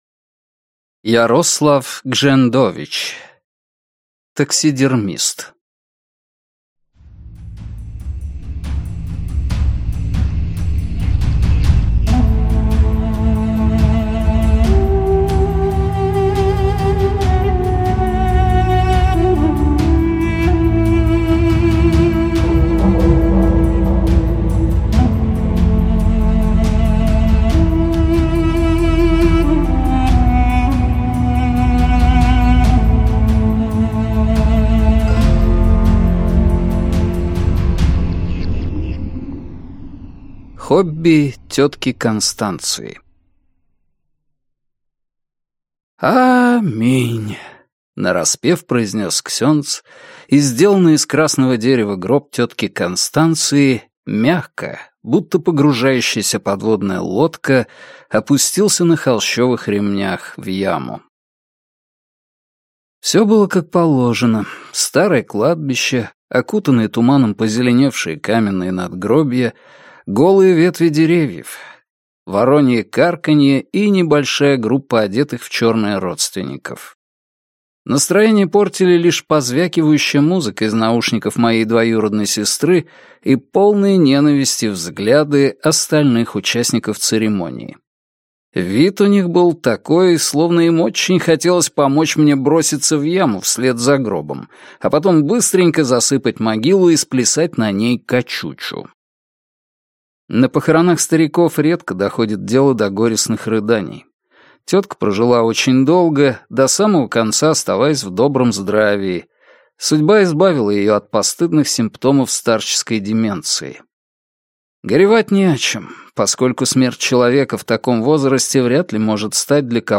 Аудиокнига Таксидермист | Библиотека аудиокниг